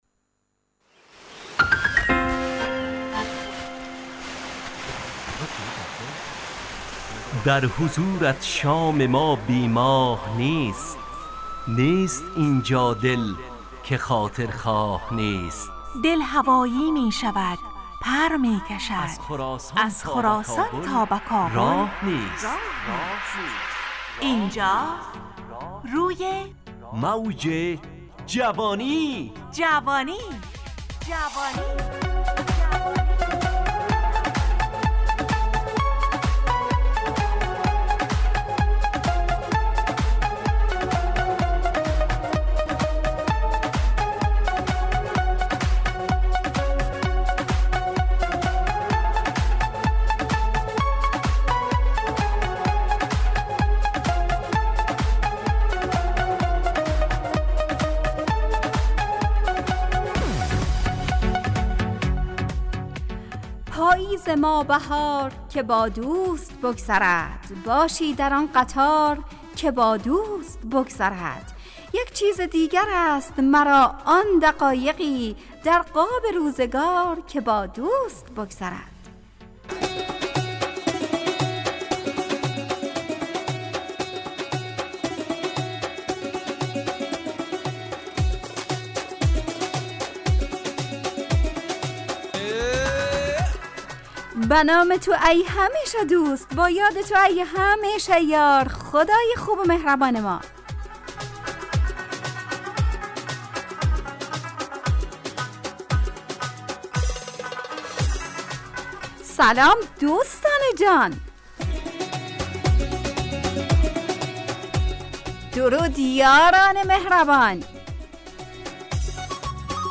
همراه با ترانه و موسیقی مدت برنامه 55 دقیقه . بحث محوری این هفته (دوستی) تهیه کننده